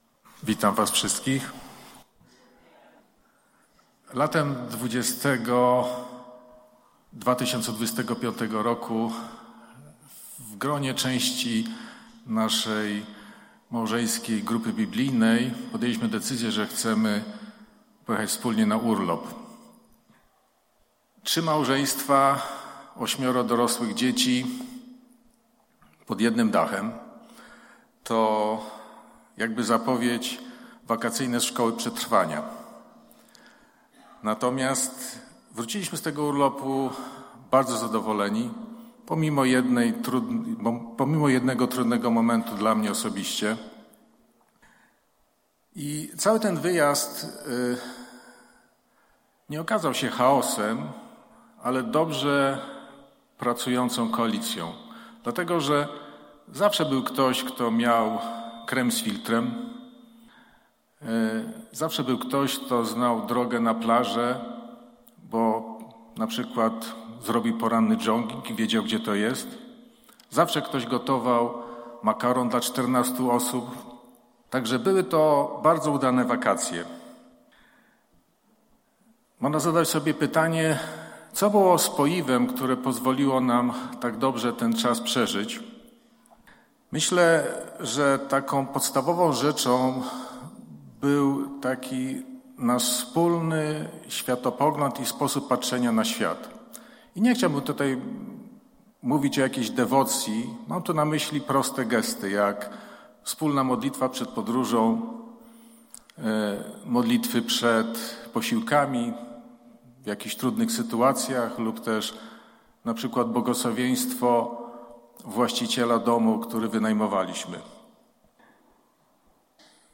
Pytania do przemyślenia po kazaniu: 1) Co w centrum twojego duchowego serca łączy cię ze Społecznością Betlejem?